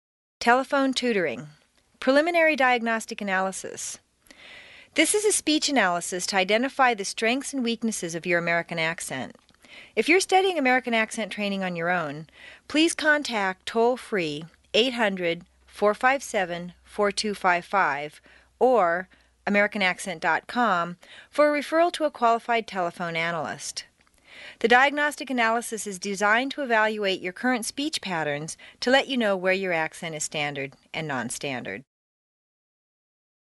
美语口音训练第一册03 听力文件下载—在线英语听力室